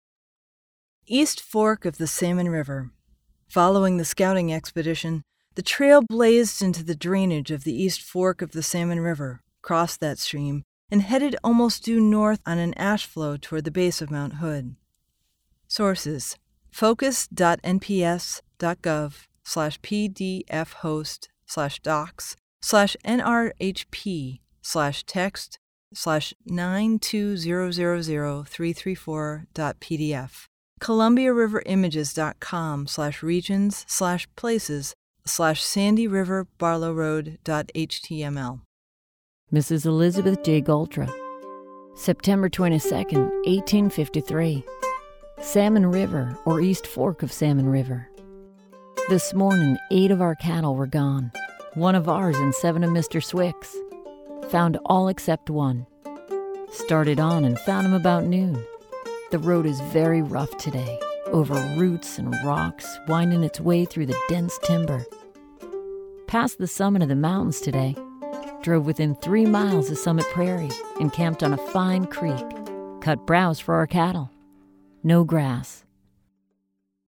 Audio: Diary excerpts: Elizabeth Goltra